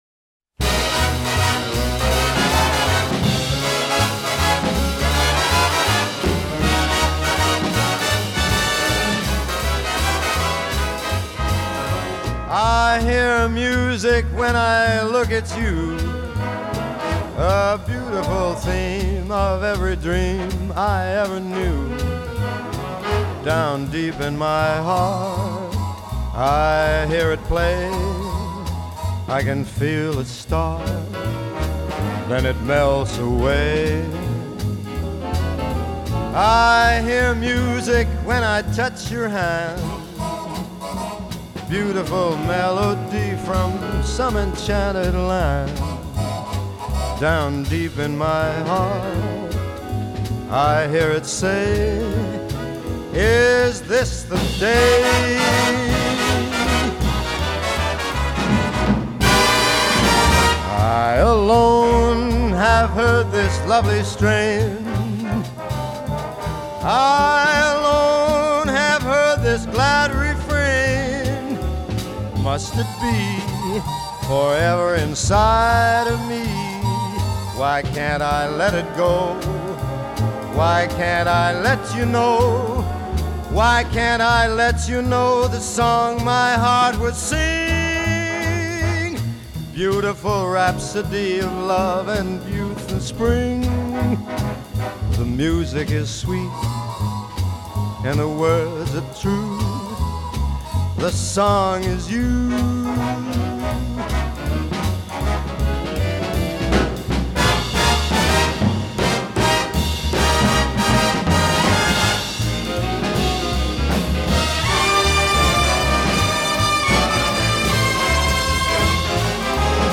1932   Genre: Musical   Artist